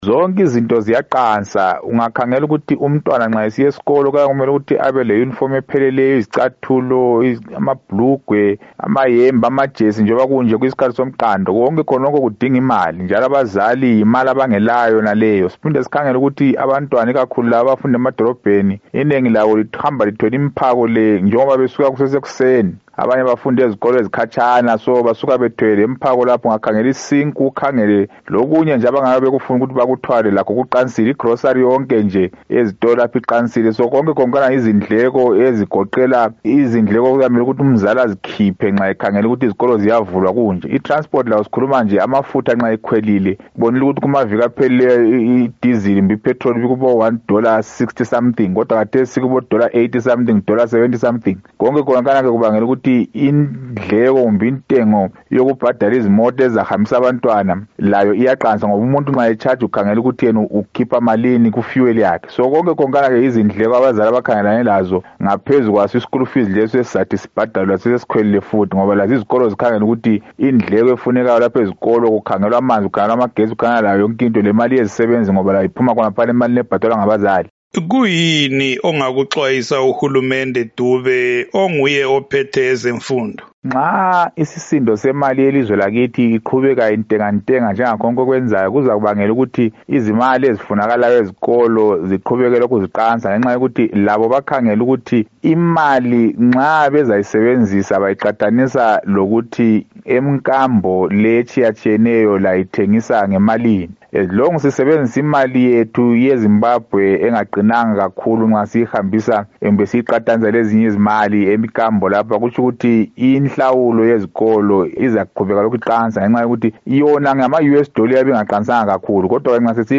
Ingxoxo